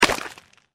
sporecarrier_foot_l02.mp3